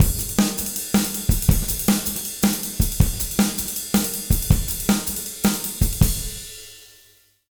160JUNGLE1-R.wav